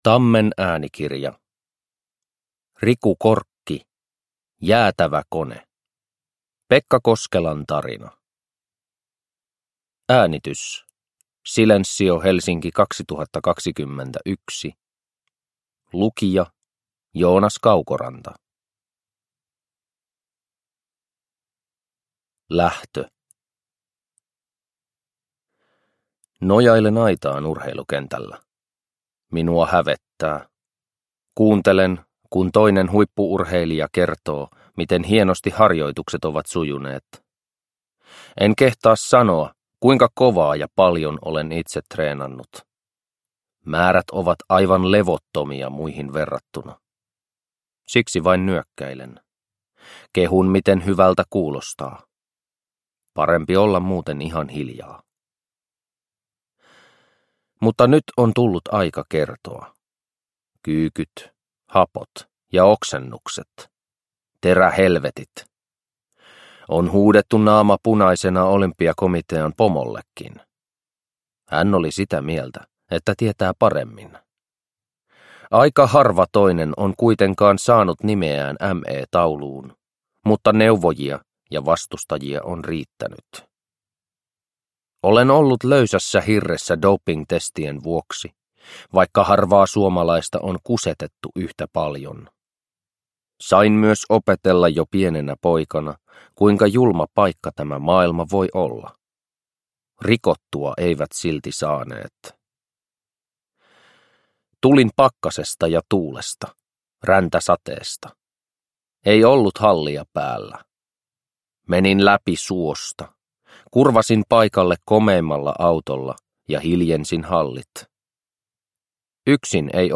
Jäätävä kone - Pekka Koskelan tarina – Ljudbok – Laddas ner